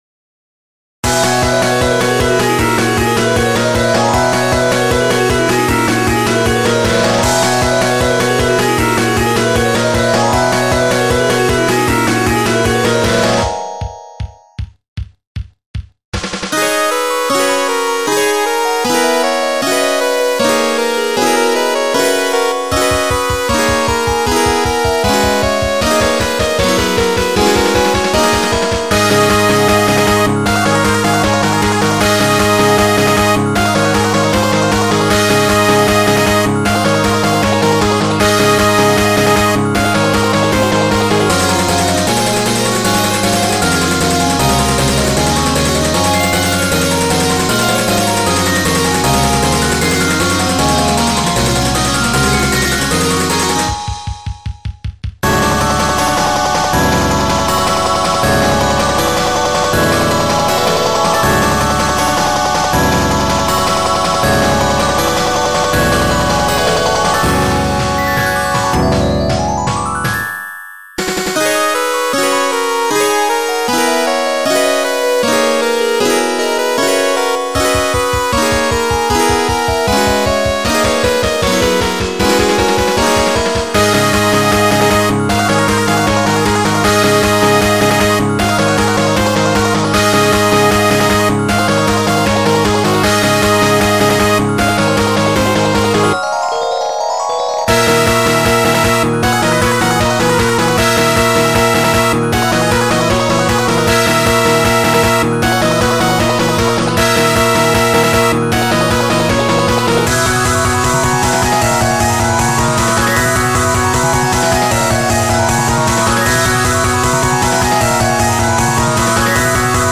結構音大きめに作成するので、音大きかったらすみません;;
ジャンル：ファンタジアロック
本当は、自分が歌う用に作成したのですが、音が余りにも高く、自分には歌えないのでインストにしました(--;)